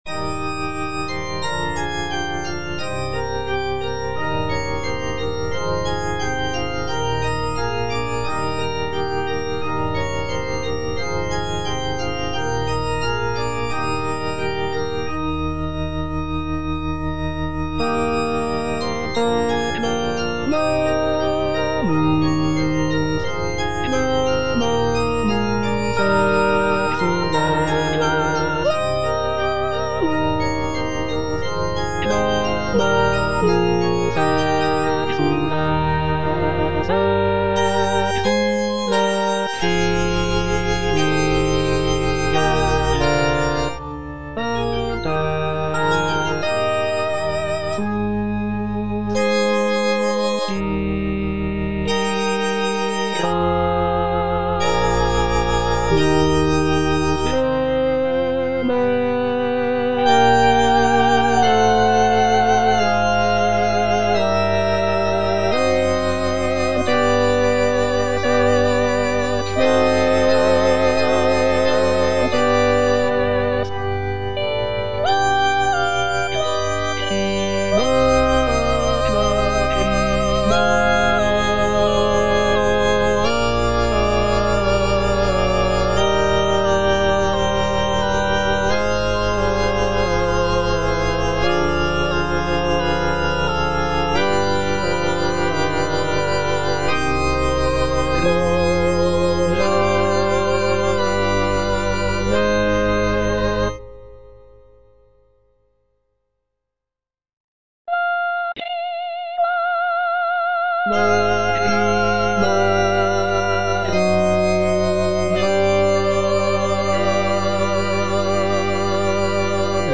G.B. PERGOLESI - SALVE REGINA IN C MINOR Ad te clamamus (All voices) Ads stop: auto-stop
"Salve Regina in C minor" is a sacred choral work composed by Giovanni Battista Pergolesi in the early 18th century. It is a setting of the traditional Marian antiphon "Salve Regina" and is known for its poignant and expressive melodies.